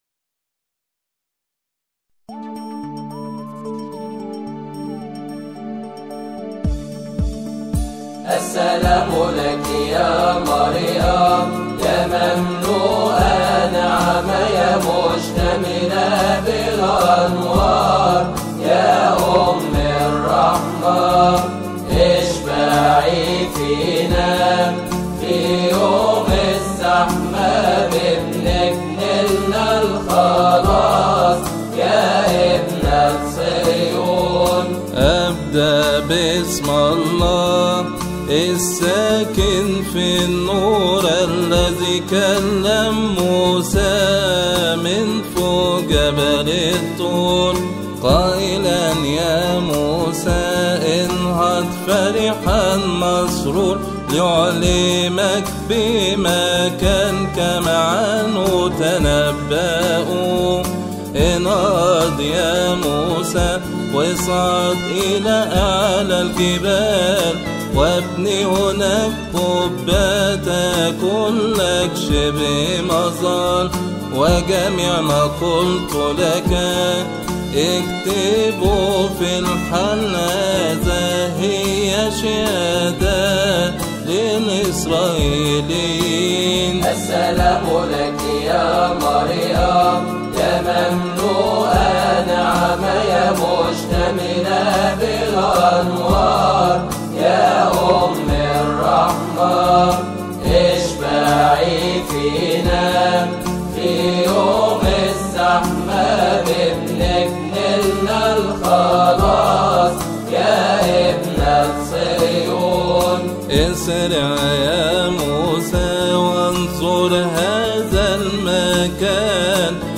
مكتبة الألحان
• المصدر : فريق أبوفام